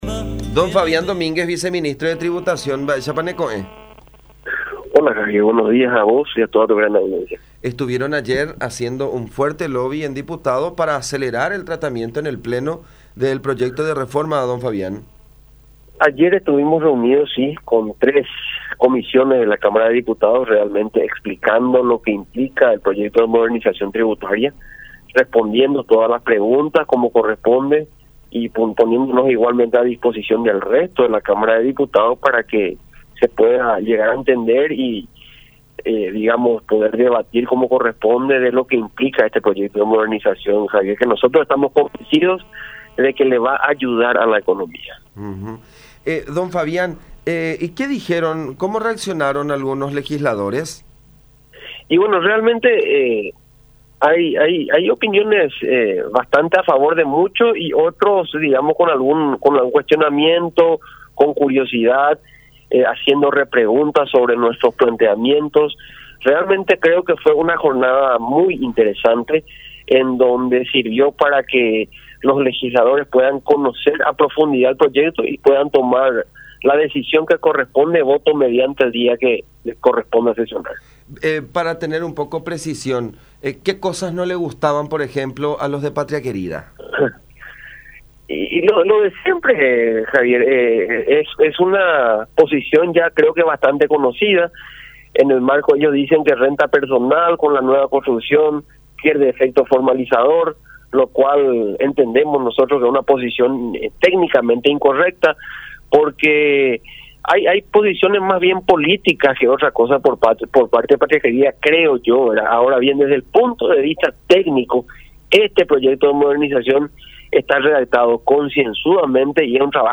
05-Fabián-Domínguez-Viceministro-de-Tributacionpy-sobre-reforma-tributaria.mp3